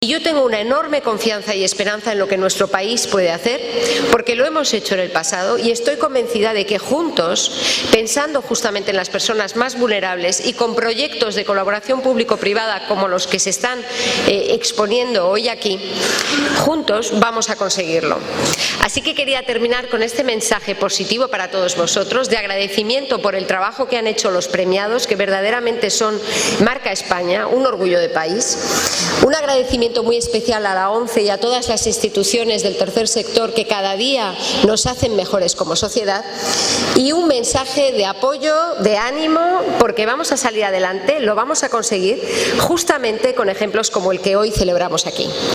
Estas fueron las palabras formato MP3 audio(0,87 MB) que la vicepresidenta primera y ministra de Asuntos Económicos y Transformación Digital,  Nadia Calviño, dedicó a las entidades impulsoras y proyectos premiados en  la gala de entrega de la VI edición de los Premios Discapnet de Fundación ONCE a las Tecnologías Accesibles, unos galardones que reconocen la accesibilidad TIC y por tanto su compromiso con las personas con discapacidad.